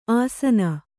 ♪ āsana